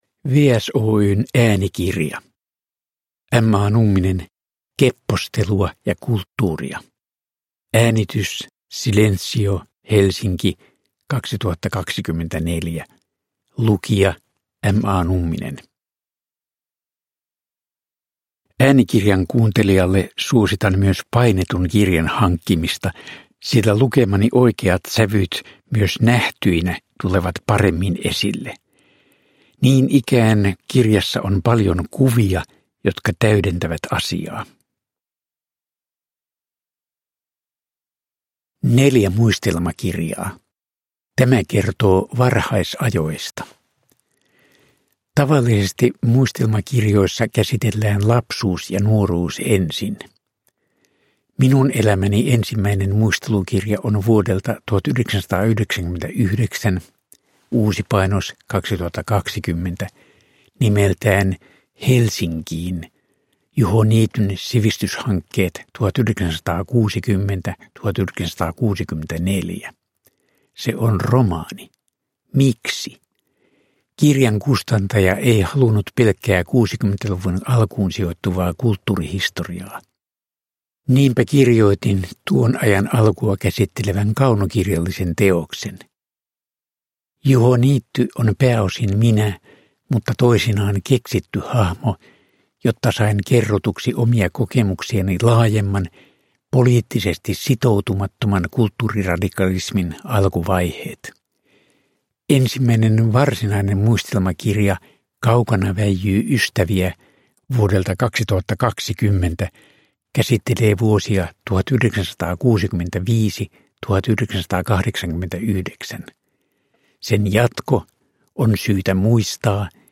Keppostelua ja kulttuuria – Ljudbok
Uppläsare: M. A. Numminen